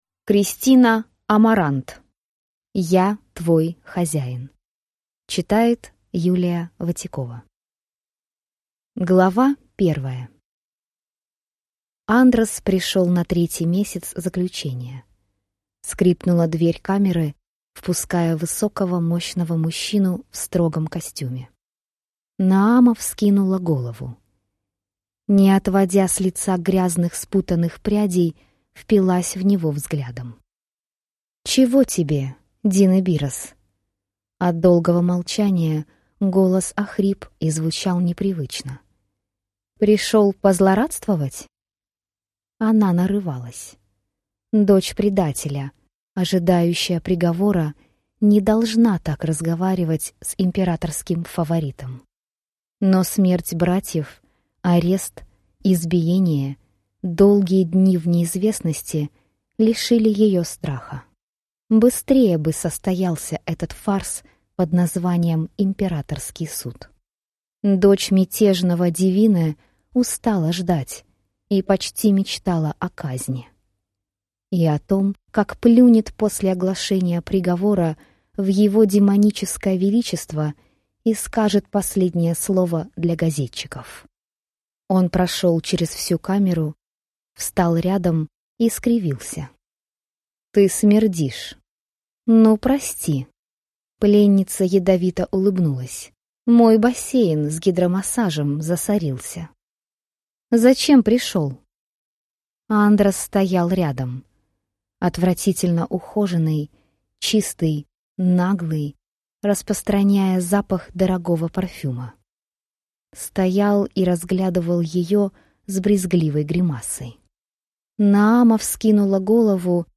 Аудиокнига Я твой хозяин!